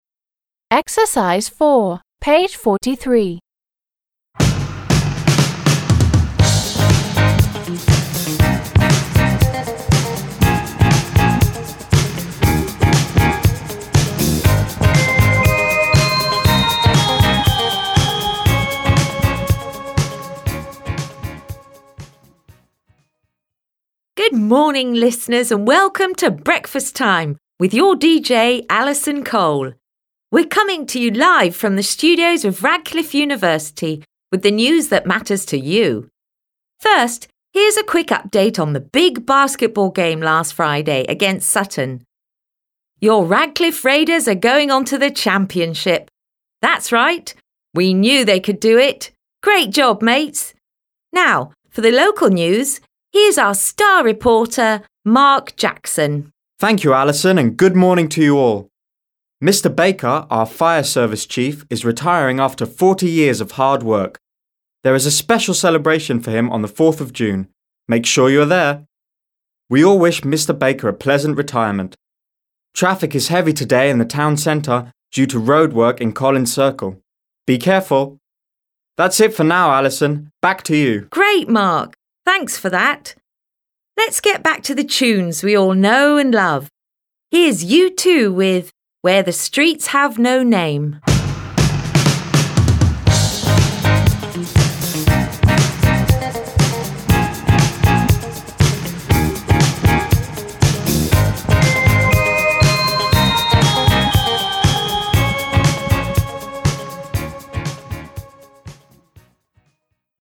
It starts with music and a greeting and presents the news about a basketball game, the Fire Service Chief’s retirement, and the traffic today.